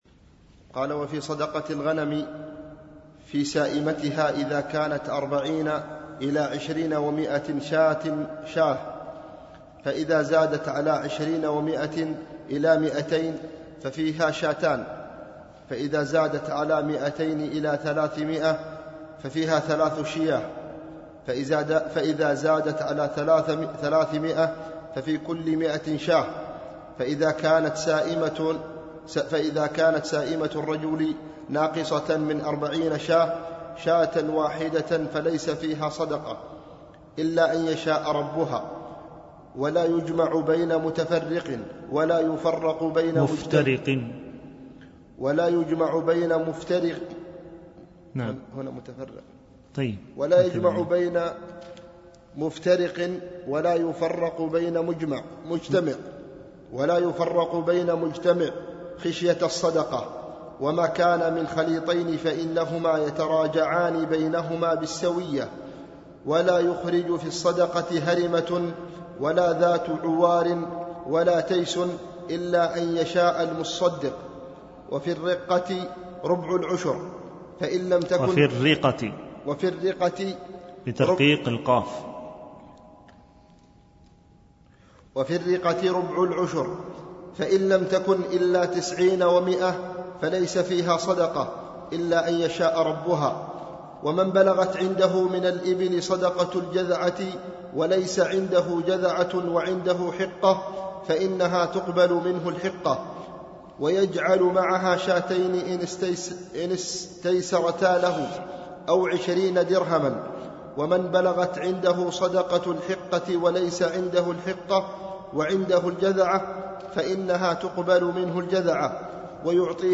دروس مسجد عائشة (برعاية مركز رياض الصالحين ـ بدبي)
MP3 Mono 22kHz 32Kbps (CBR)